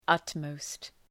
Προφορά
{‘ʌt,məʋst}